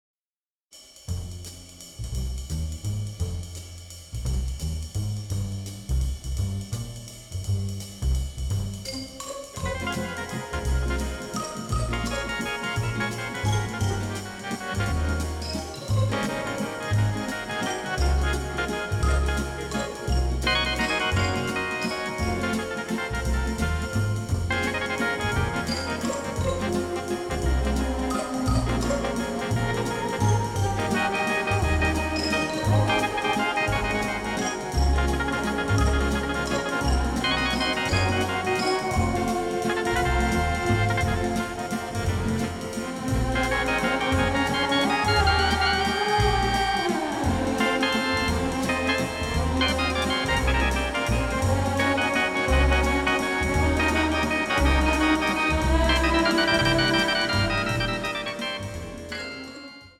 vibrant, funny, powerfully melodic
The recording took place at CTS Studios in Bayswater